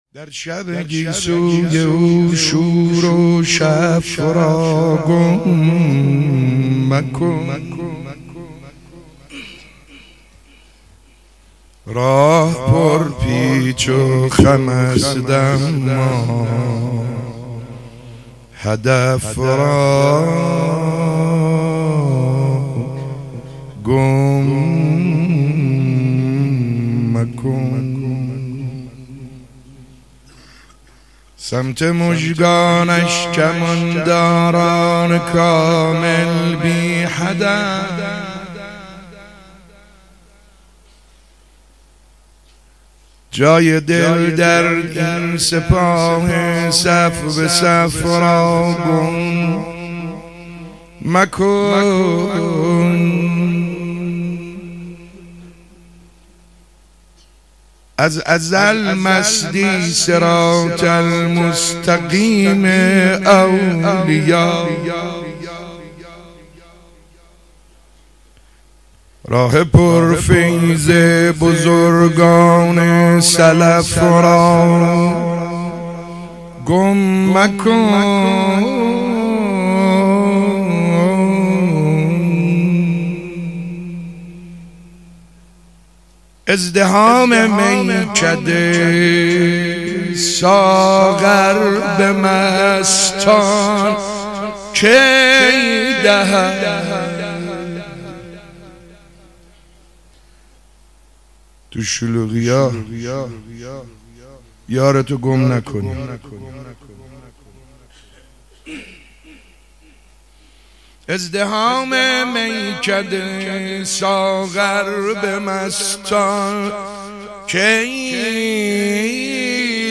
روزهشتم محرم95_مدح خوانی_